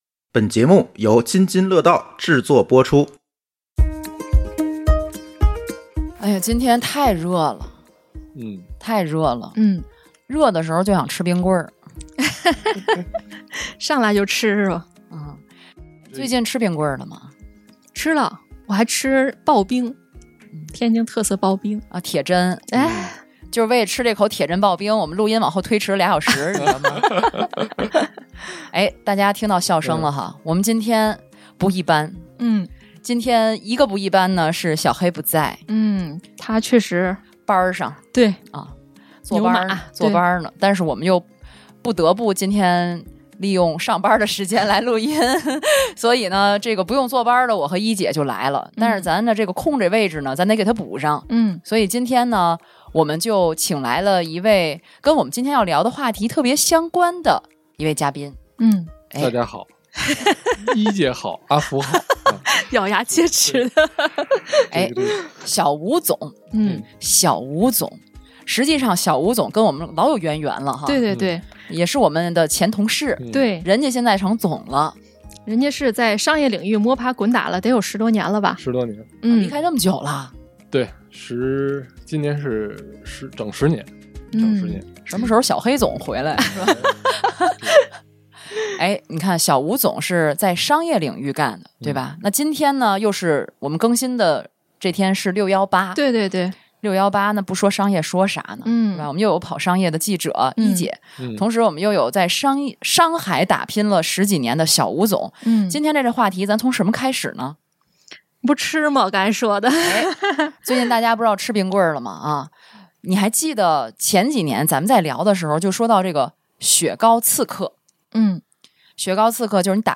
场地支持 / 声湃轩天津录音间